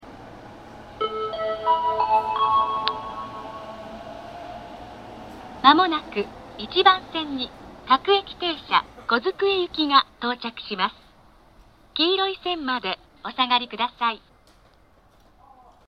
東海道型(女性)
接近放送 各駅停車　小机行の接近放送です。
日産スタジアムでイベントがあった際に収録しました。
夜間音量なので音量が小さいです。